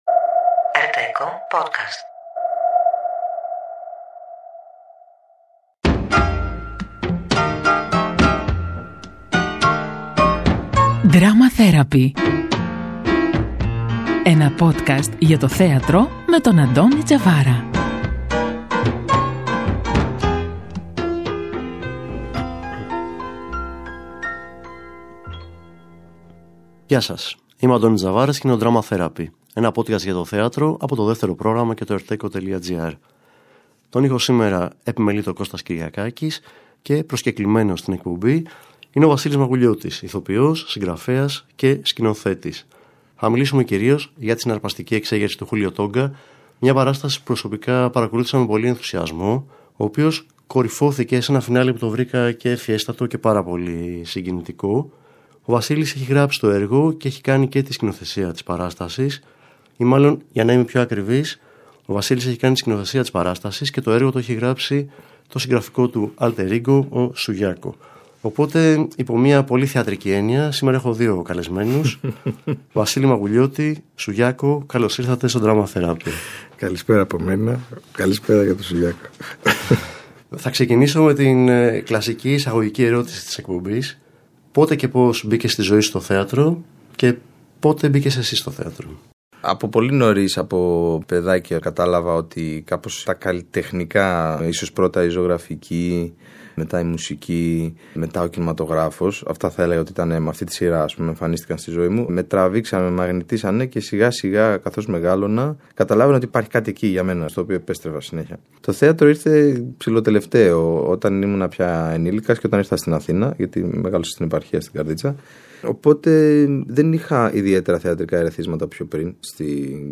Ένα podcast για το θέατρο από το Δεύτερο Πρόγραμμα και το ERTecho Στο Drama Therapy άνθρωποι του θεάτρου, κριτικοί και θεατές συζητούν για τις παραστάσεις της σεζόν αλλά και για οτιδήποτε μπορεί να έχει ως αφετηρία ή ως προορισμό τη θεατρική πράξη.